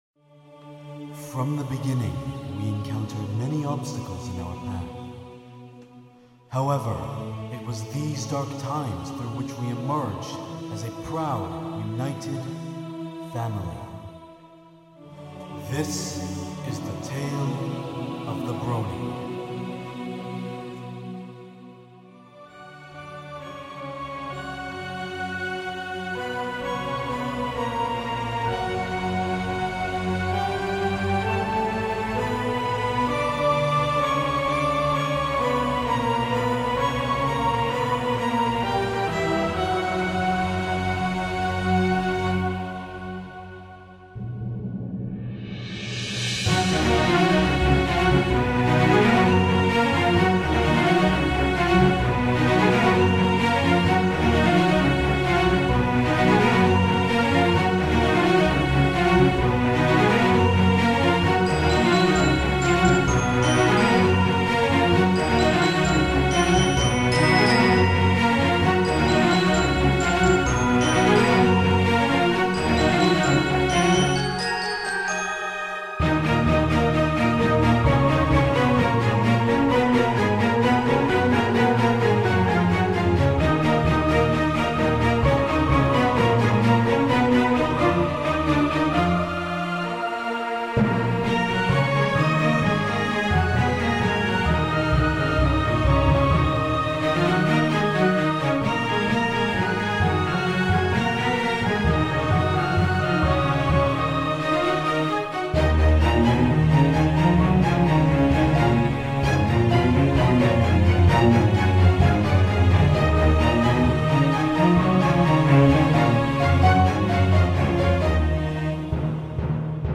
(This one is finally levelled correctly, phew :D)